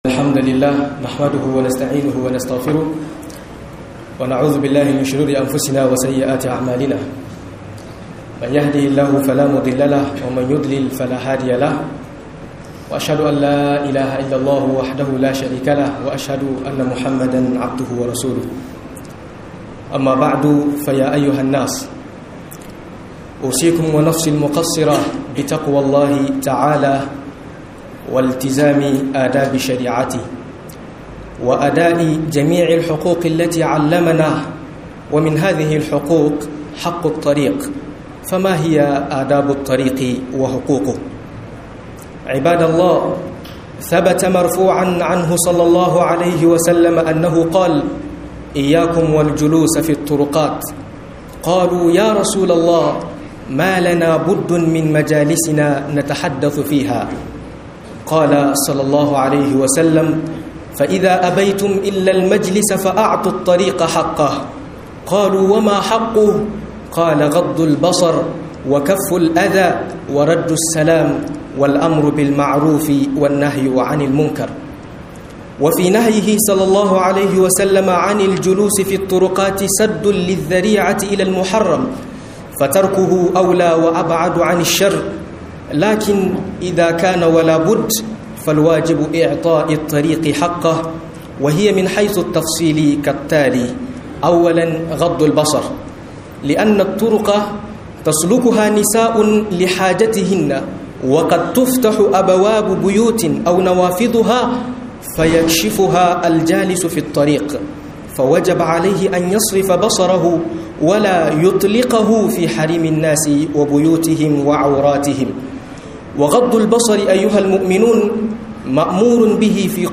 (Hanya) hakkokin hanya da ladduban ta - MUHADARA